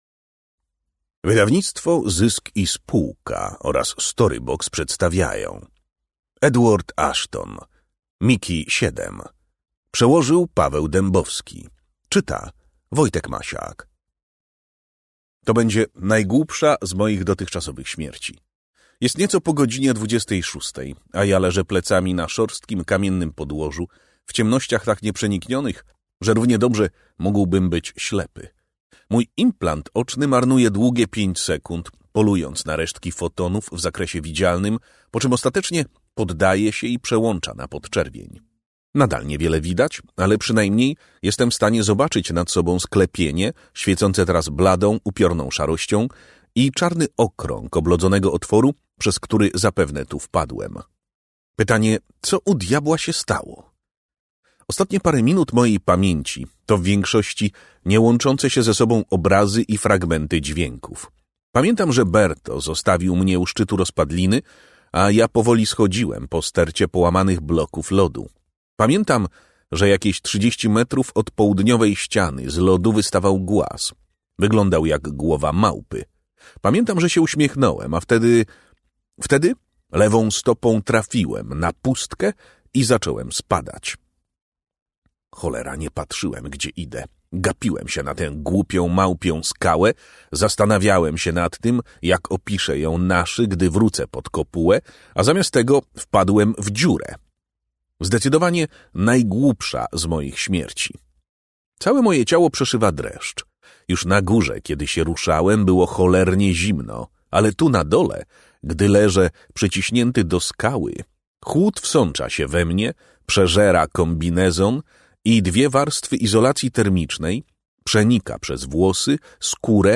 Mickey7 [okładka filmowa] - Edward Ashton - audiobook